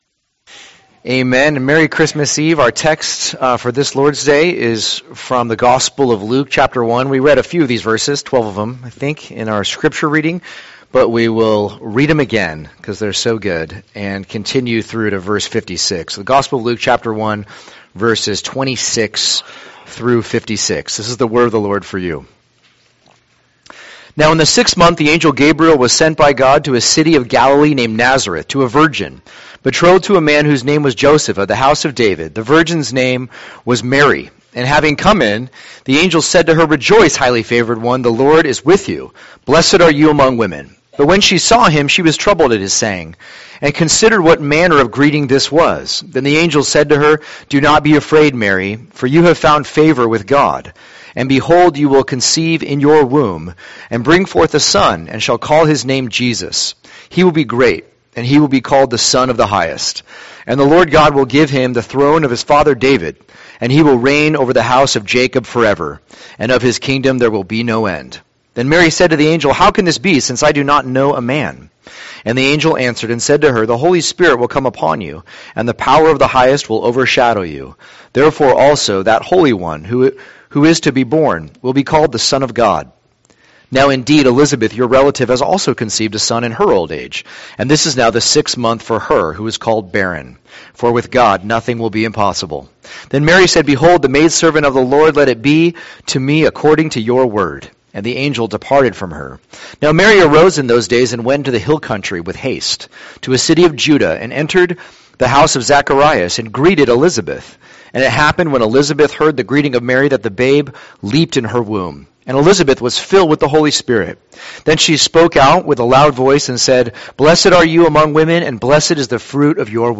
2023 The Song of Mary Preacher